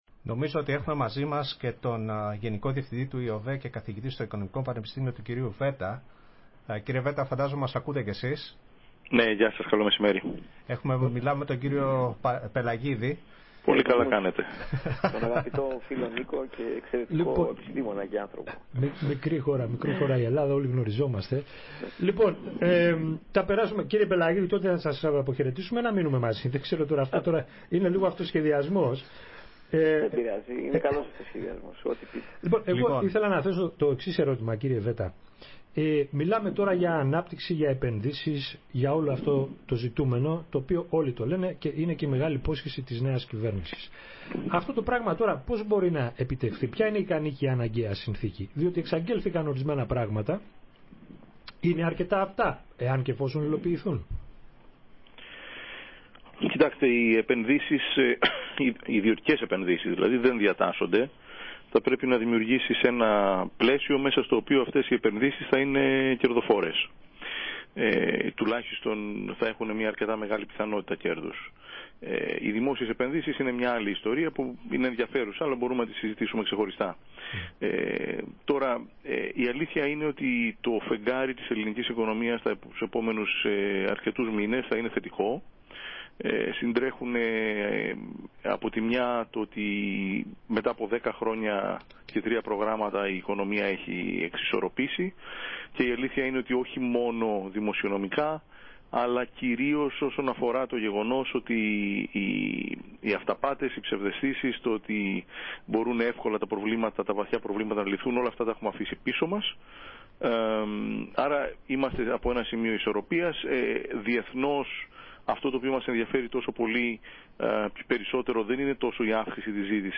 Συνέντευξη
στη ραδιοφωνική εκπομπή «Καθαροί Λογαριασμοί»